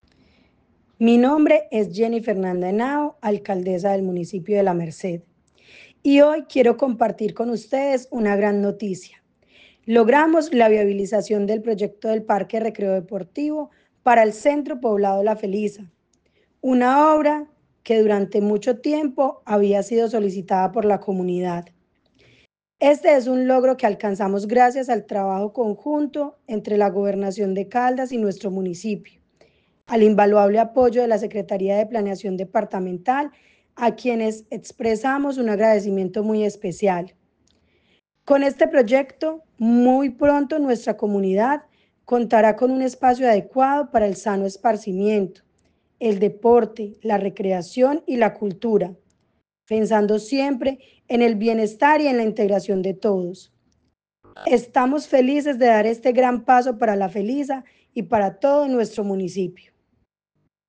Yeni Fernanda Henao Dávila, alcaldesa del municipio de La Merced
Yeni-Henao-Alcaldesa-La-Merced.mp3